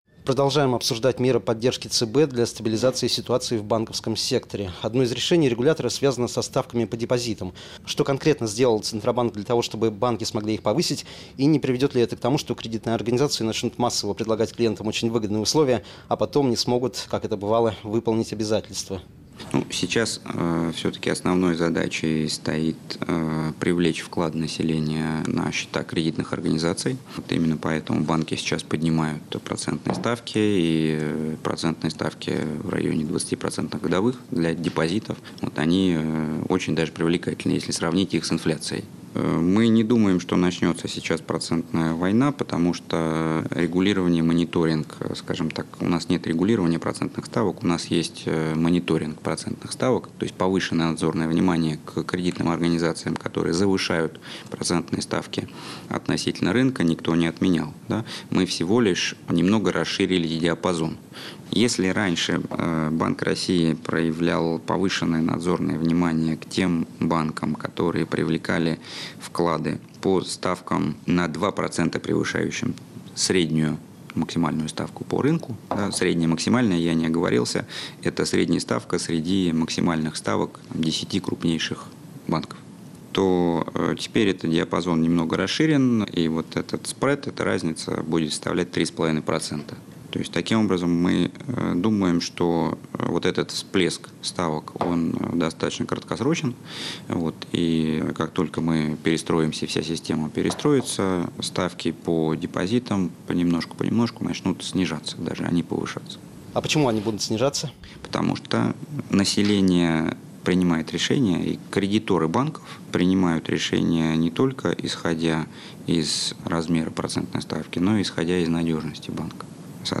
Интервью
Интервью заместителя Председателя Банка России В.А. Поздышева радиостанции «Бизнес ФМ» 22 декабря 2014 года (часть 2)